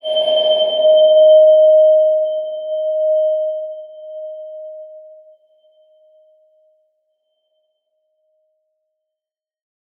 X_BasicBells-D#3-pp.wav